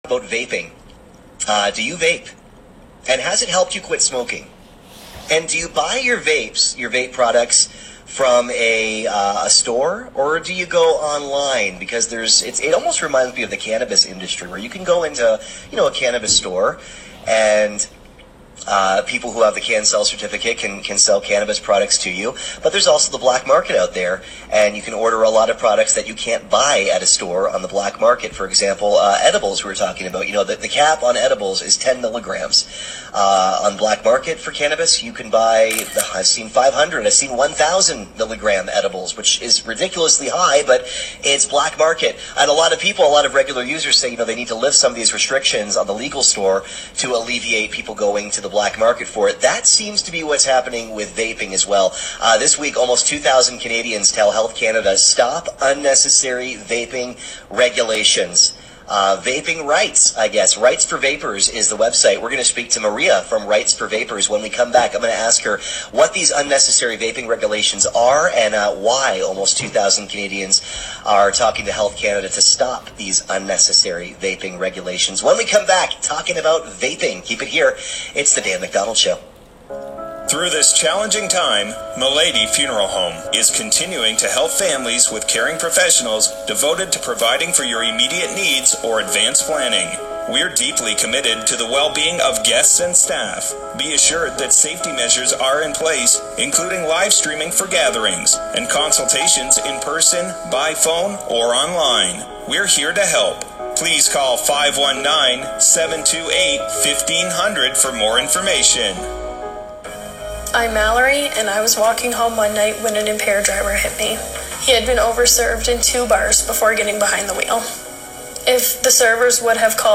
Interview AM800